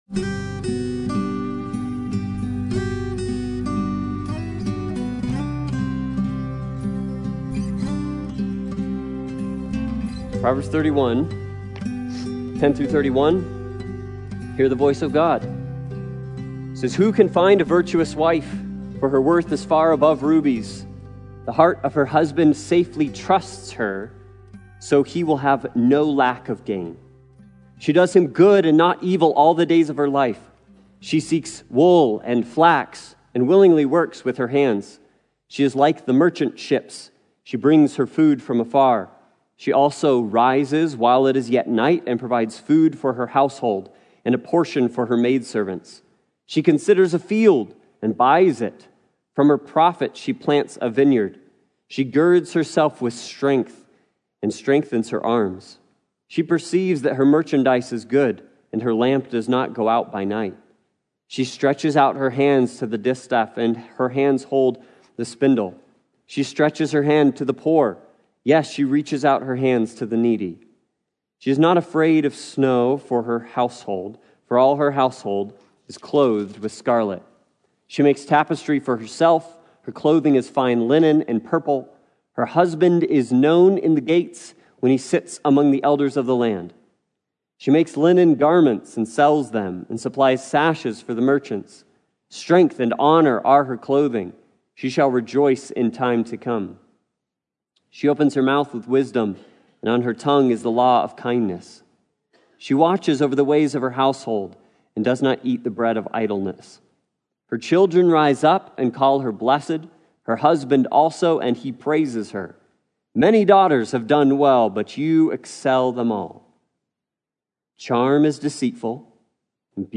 Proverbs 31:10-31 Service Type: Sunday Morning Worship « Living Hope